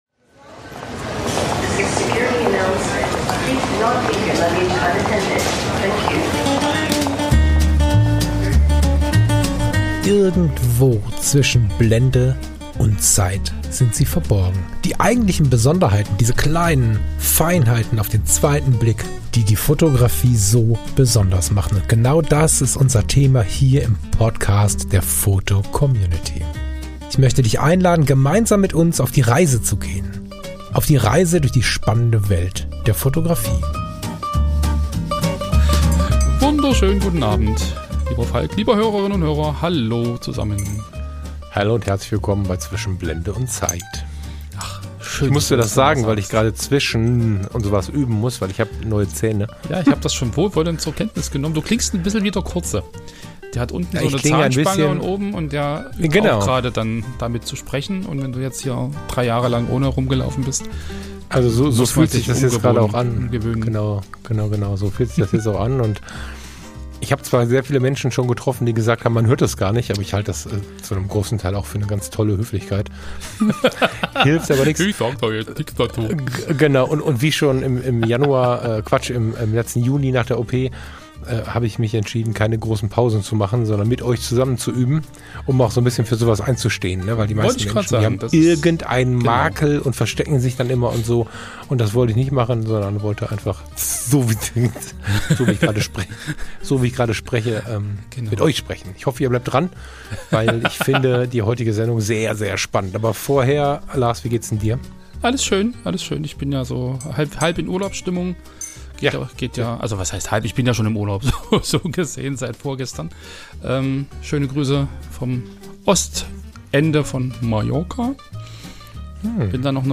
Und so wird aus dem gemeinsamen Filmschauen ein sehr eigenes Gespräch über Fotografie, Erschöpfung, Wahrnehmung und die Sehnsucht nach etwas Echtem.